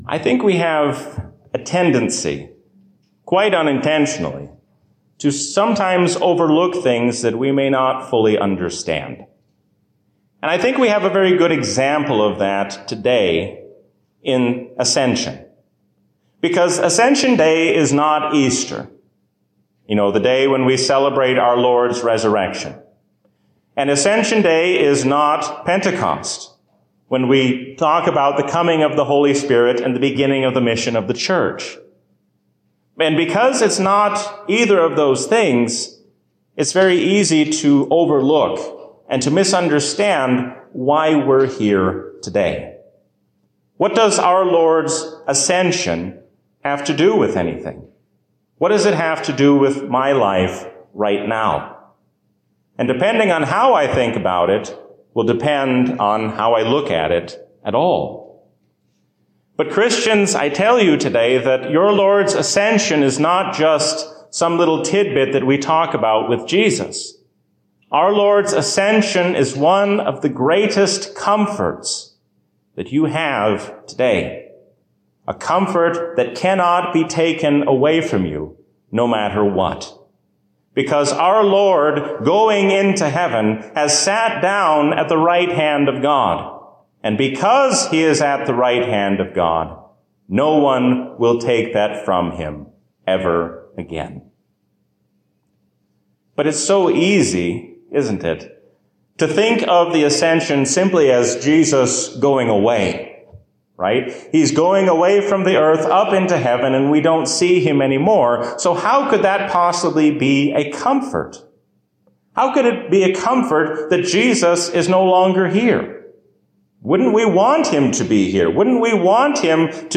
A sermon from the season "Trinity 2023." When we keep our eyes fixed on God, we will be able to face whatever may come in the future.